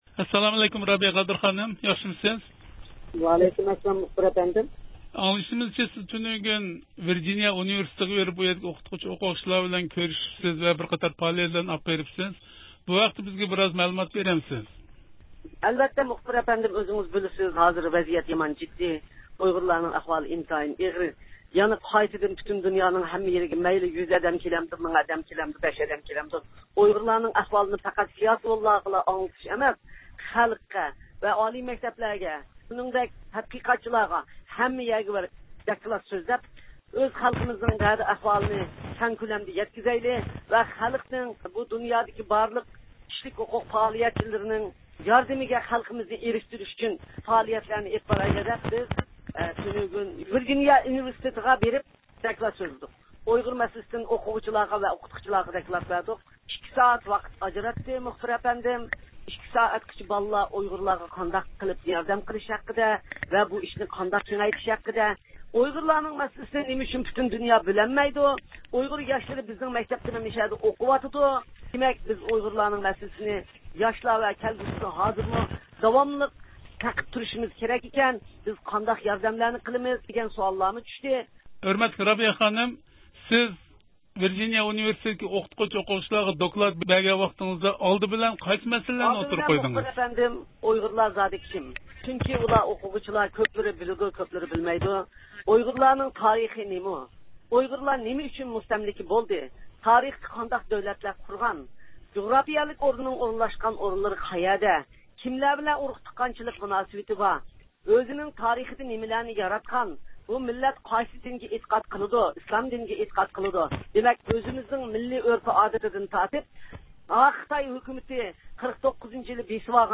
يۇقىرىدىكى ئۇلىنىشتىن، مۇخبىرىمىزنىڭ بۇ ھەقتە رابىيە خانىم بىلەن ئۆتكۈزگەن سۆھبىتىنى ئاڭلايسىلەر.